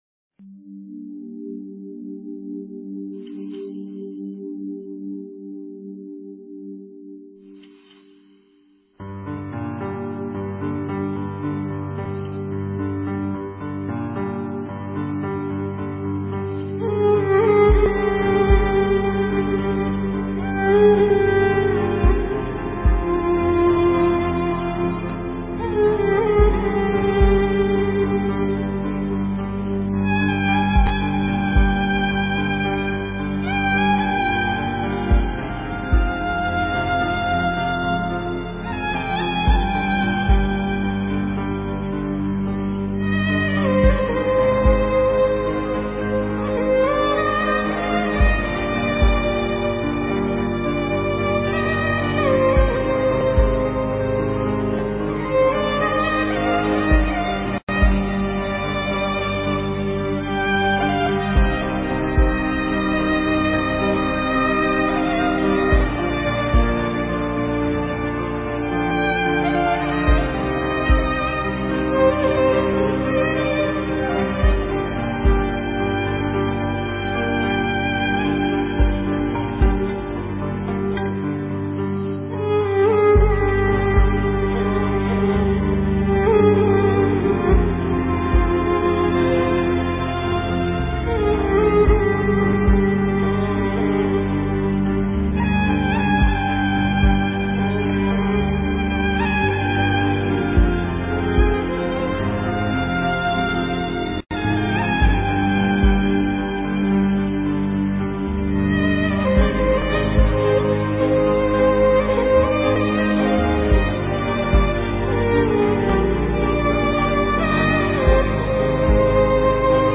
ویلون غمگین.mp3
ویلون-غمگین.mp3